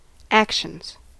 Ääntäminen
Ääntäminen US : IPA : [ˈæk.ʃənz] Haettu sana löytyi näillä lähdekielillä: englanti Actions on sanan action monikko.